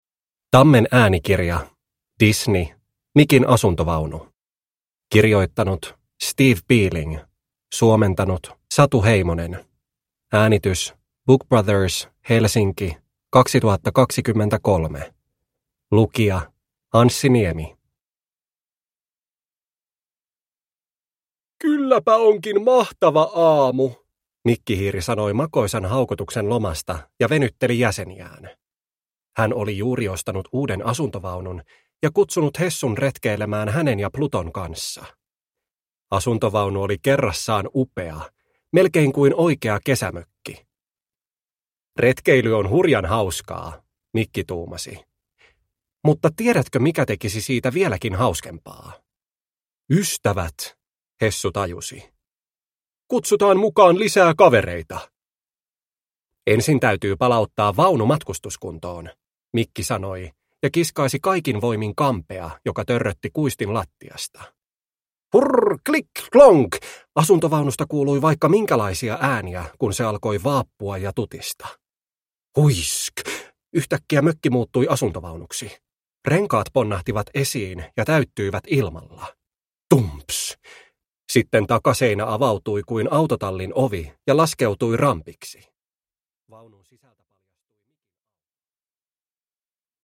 Disney. Mikin asuntovaunu – Ljudbok – Laddas ner